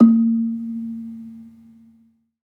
Gambang-A#2-f.wav